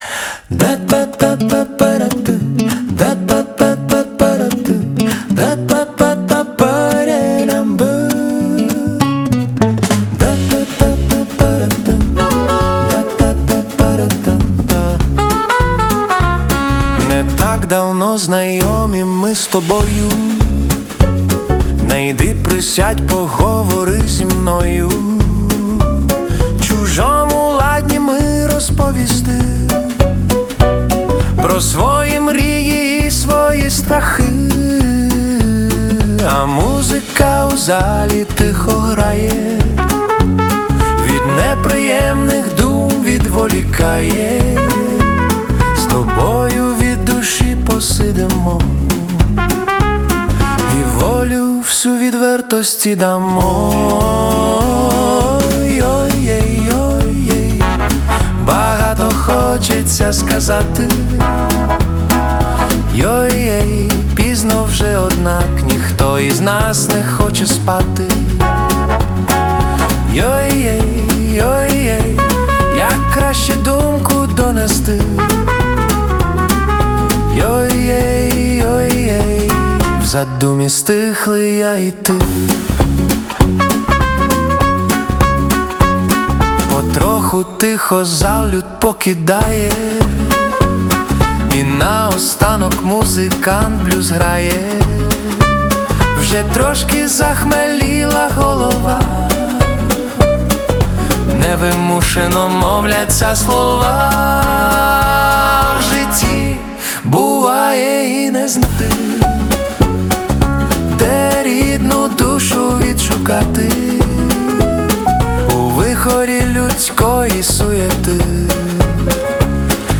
Стиль: Фолк-поп